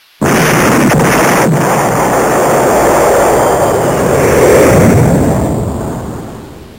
explosion 2